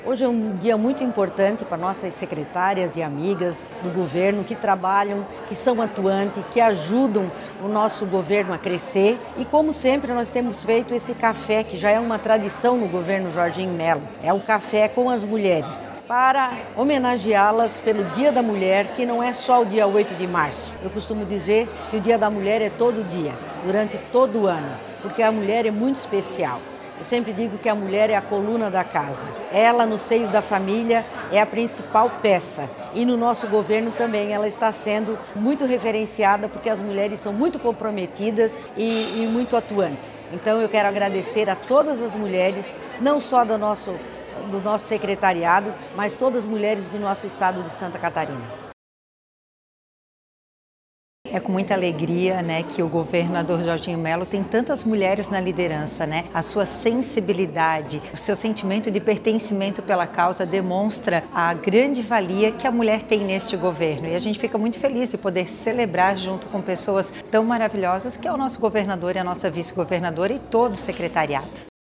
O evento foi realizado na Casa d’Agronômica, em Florianópolis, e reuniu cerca de 70 mulheres que atuam no Governo de Santa Catarina.
O encontro teve como objetivo reconhecer a dedicação e o protagonismo das mulheres que contribuem diariamente para a gestão pública catarinense, como ressalta a vice-governadora Marilisa Boehm
A secretária adjunta de Estado da Educação, Patrícia Lueders, destaca o grande número de mulheres em posição de liderança dentro do Governo do Estado: